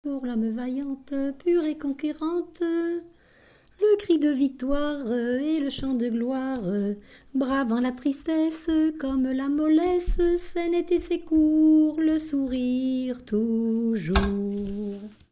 Chanté lors des rassemblements de groupements catholiques
chanteur(s), chant, chanson, chansonnette
Genre strophique
Pièce musicale inédite